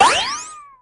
rick_bullet_01.ogg